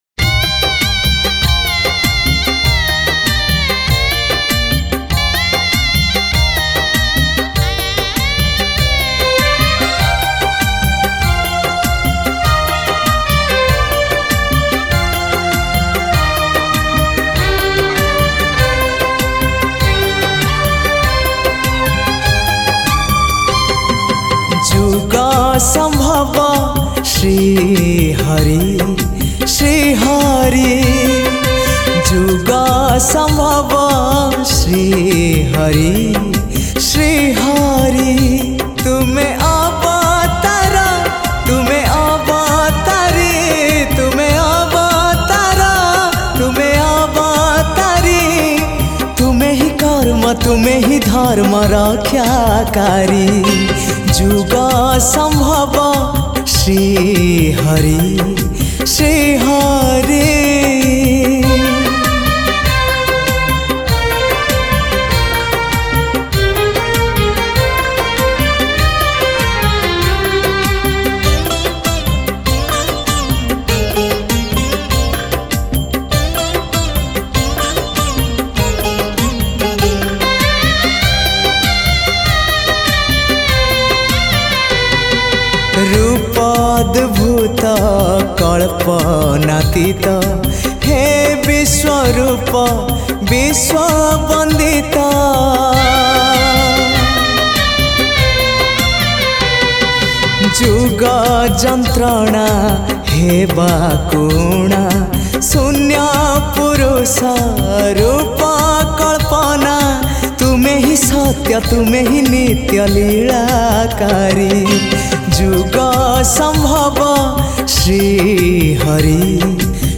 Odia Bhajan Songs